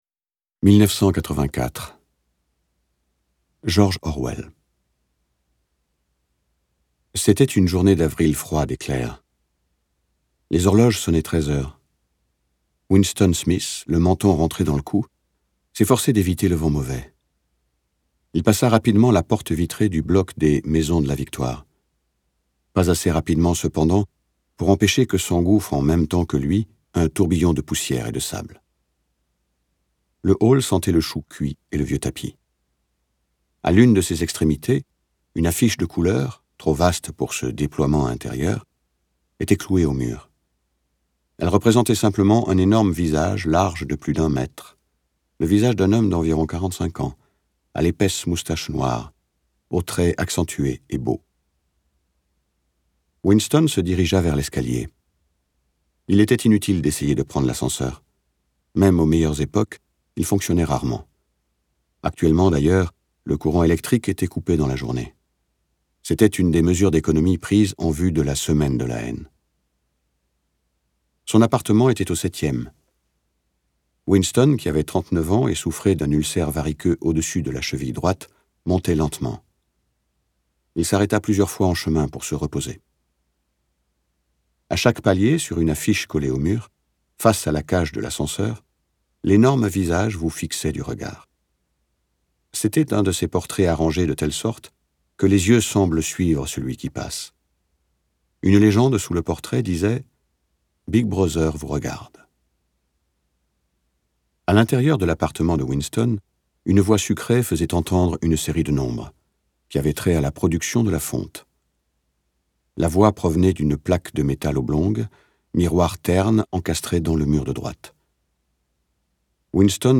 George Orwell en livre audio